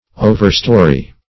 Overstore \O`ver*store"\, v. t.
overstore.mp3